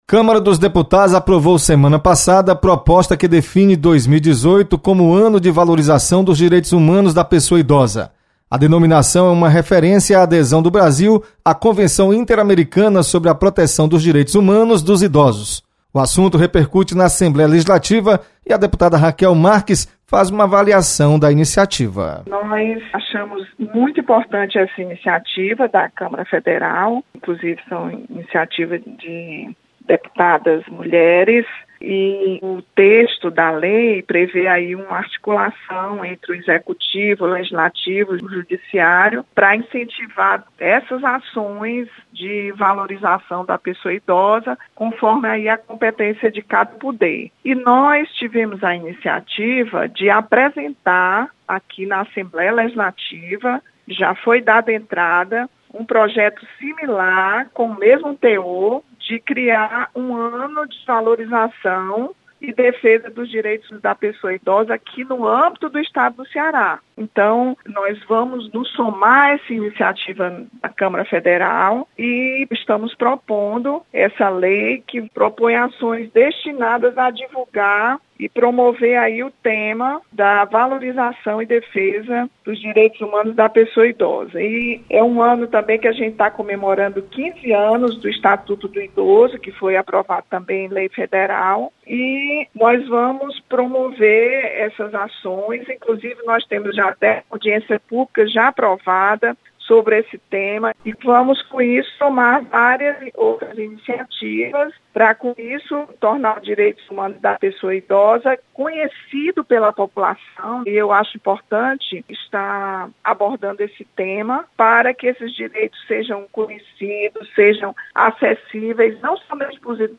Deputada defende 2018 como ano de valorização do idoso. Repórter